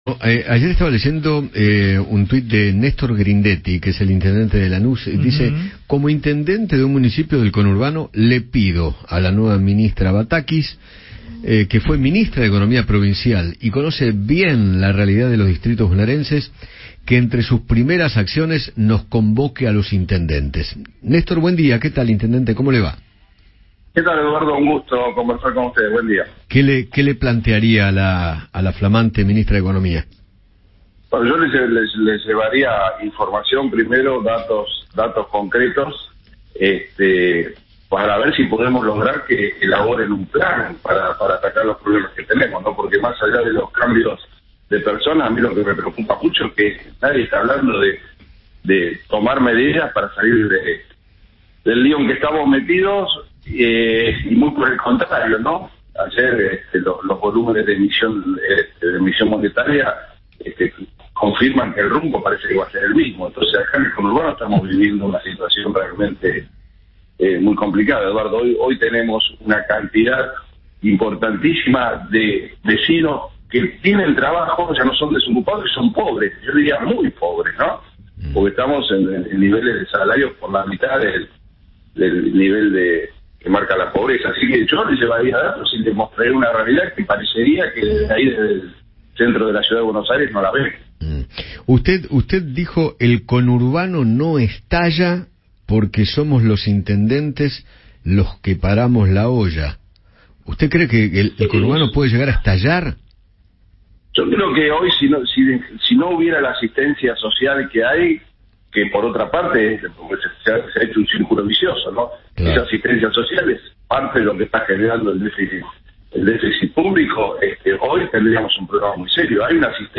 Néstor Grindetti, intendente de Lanús, conversó con Eduardo Feinmann sobre las modificaciones en el ministerio de economía y le dedicó un fuerte mensaje a Silvina Batakis.